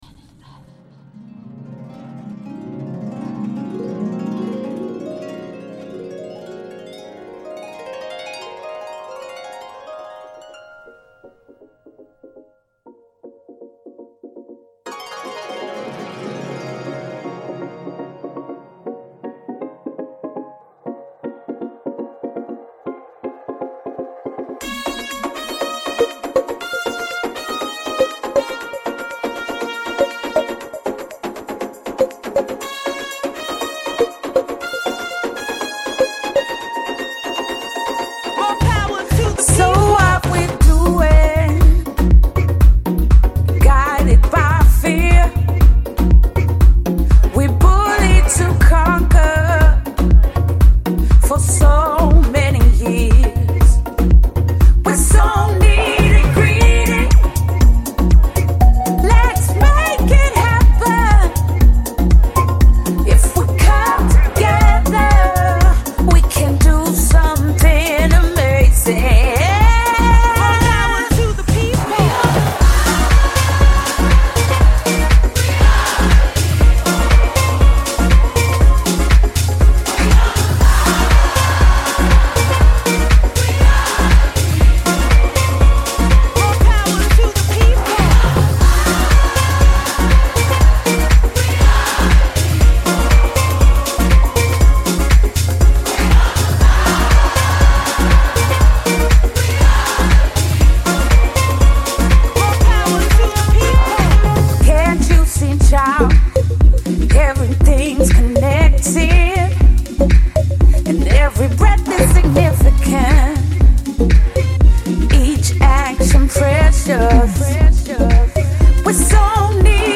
House
almost euphoric aural climax.